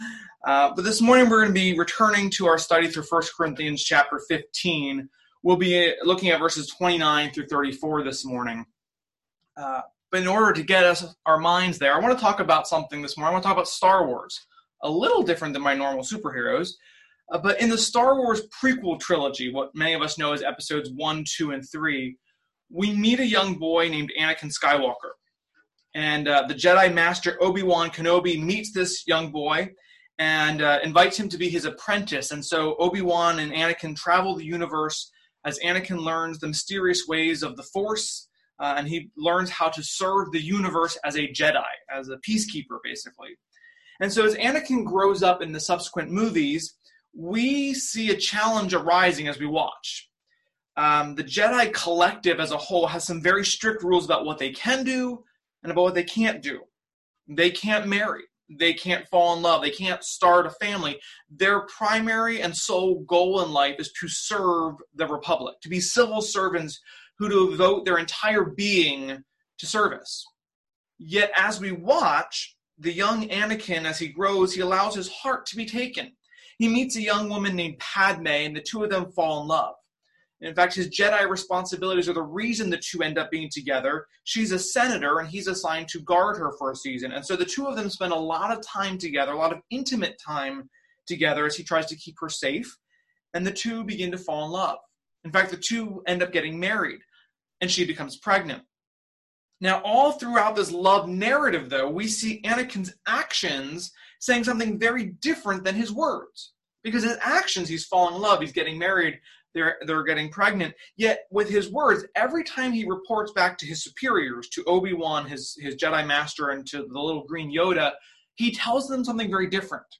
Sermon-5.17.20.mp3